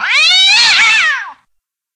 PixelPerfectionCE/assets/minecraft/sounds/mob/cat/hitt1.ogg at ca8d4aeecf25d6a4cc299228cb4a1ef6ff41196e